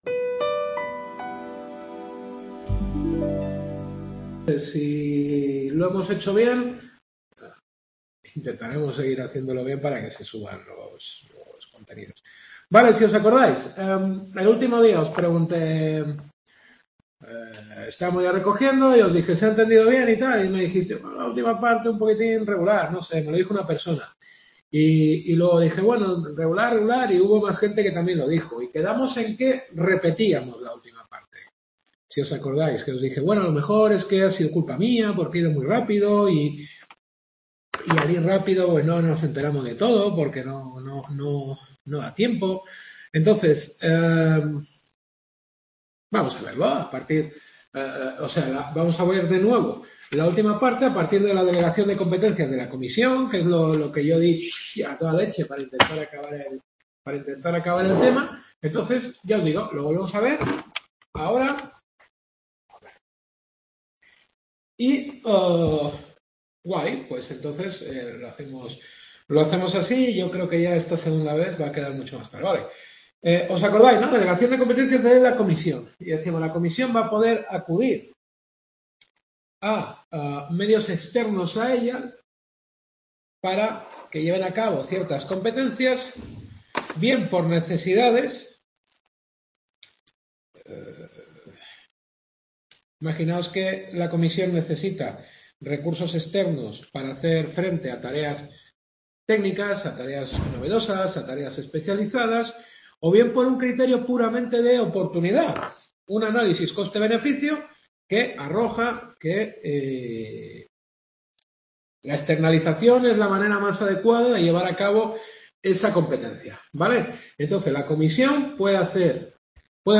Derecho administrativo europeo. Quinta clase.